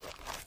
HauntedBloodlines/STEPS Dirt, Walk 01.wav at main
STEPS Dirt, Walk 01.wav